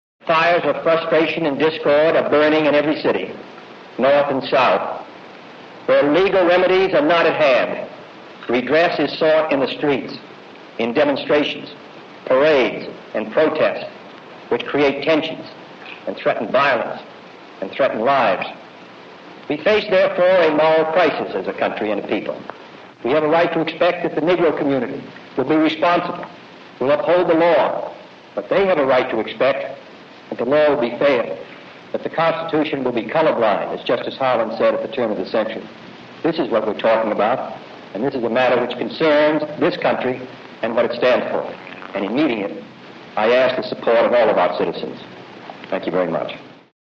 Discours de J.F. Kennedy